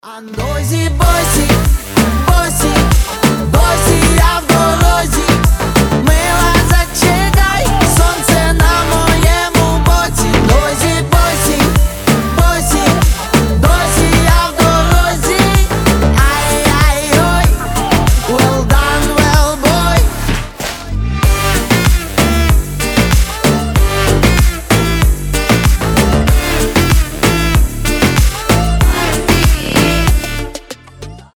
• Качество: 320, Stereo
украинские